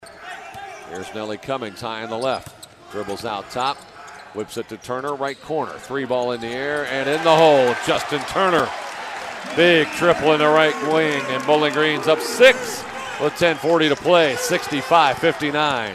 RADIO CALLS